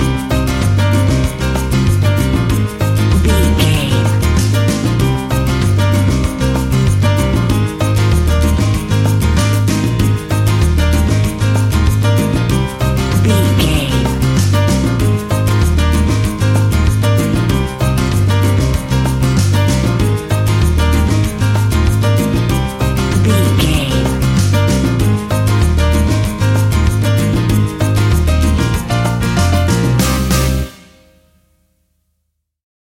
An exotic and colorful piece of Espanic and Latin music.
Aeolian/Minor
maracas
percussion spanish guitar
latin guitar